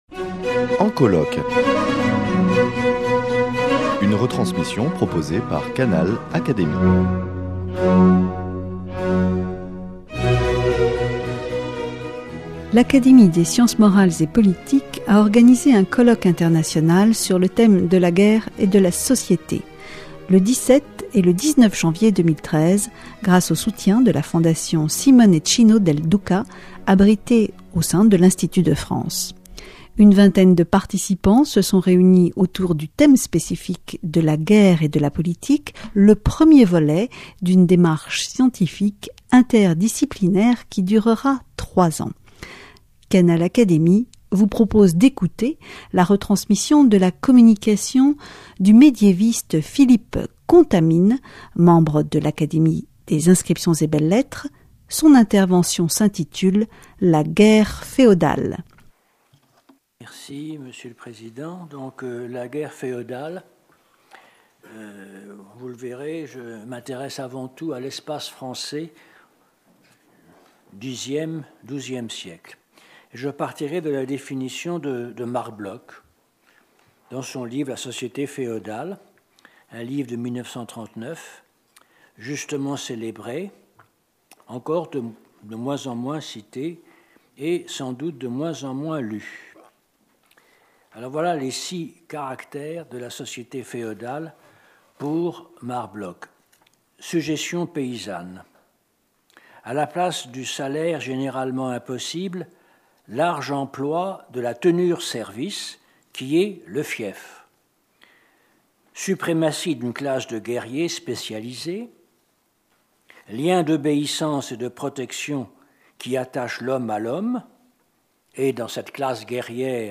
Canal Académie vous propose d’écouter la retransmission de la communication du médiéviste Philippe Contamine, membre de l’Académie des inscriptions et belles-lettres.